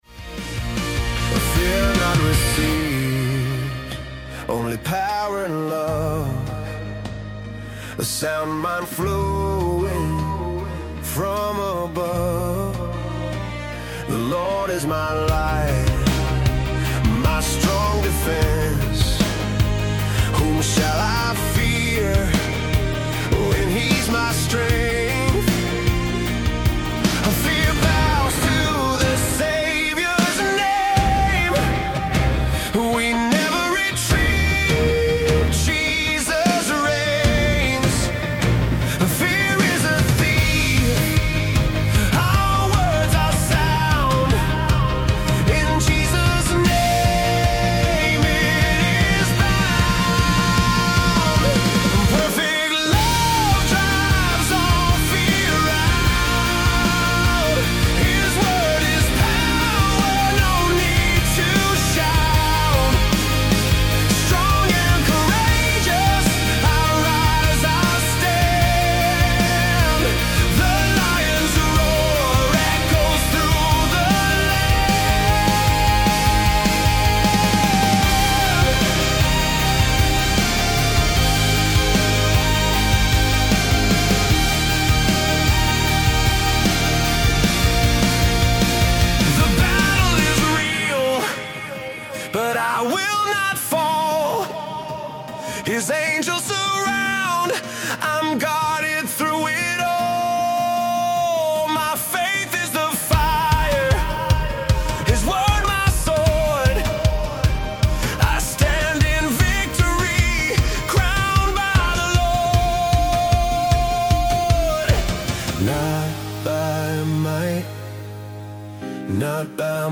energetic cinematic rock anthem
• Genre: Cinematic Rock / Christian Anthem